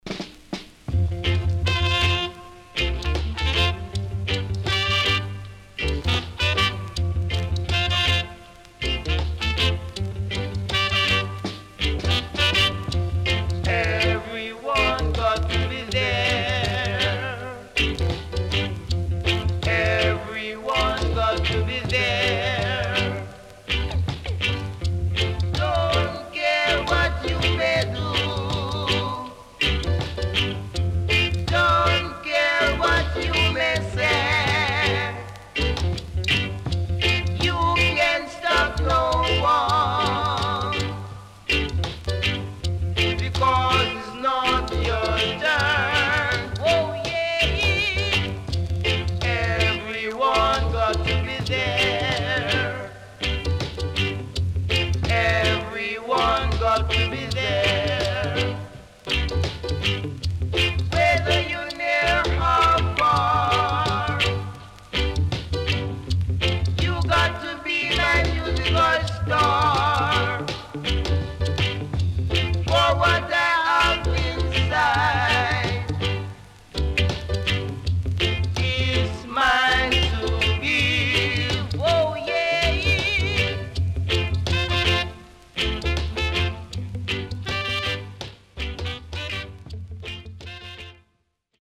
CONDITION SIDE A:VG(OK)
SIDE A:軽いヒスノイズ入ります。所々チリプチノイズ入ります。